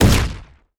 etfx_explosion_rocket.wav